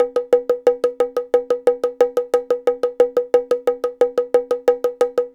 Bongo Q Note Roll.wav